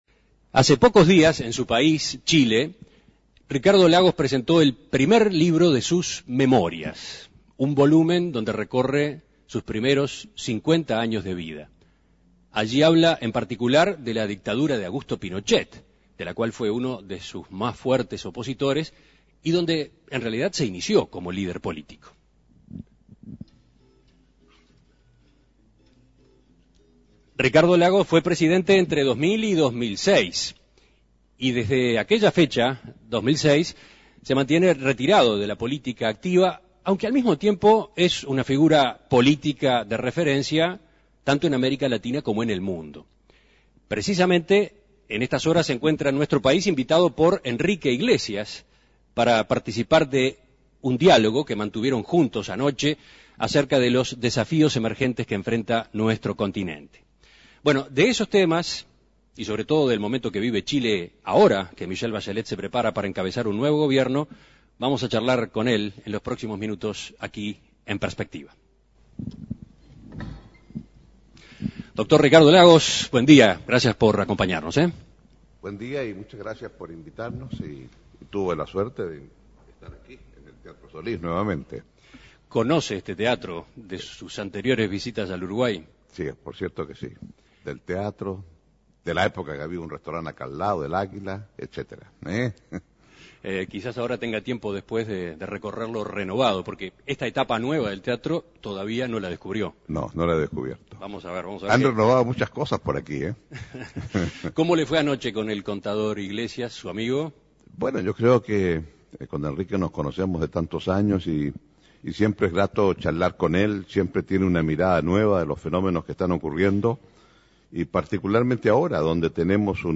En estos días se encuentra en nuestro país y En Perspectiva lo entrevistó para conocer su visión sobre la actualidad de Chile, el camino que tiene Michelle Bachelet por delante y los desafíos del continente.